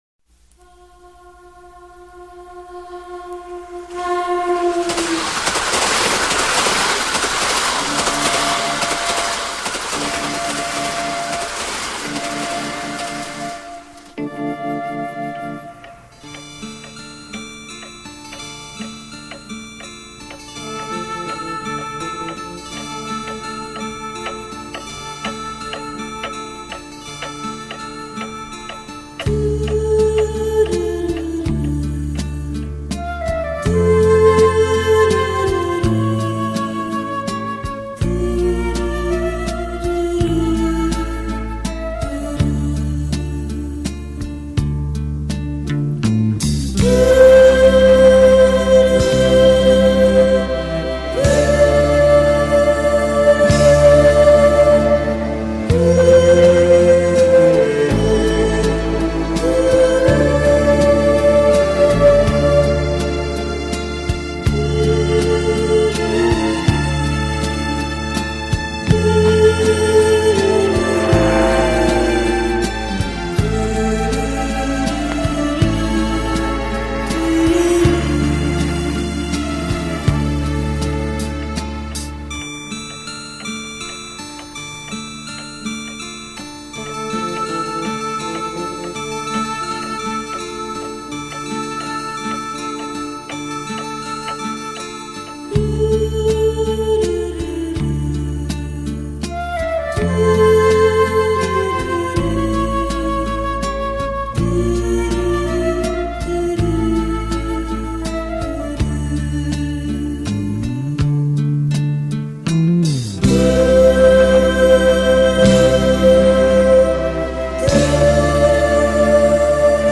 키보드
어쿠스틱 기타
신디사이저&하모니카
오보에
첼로
플룻
베이스
드럼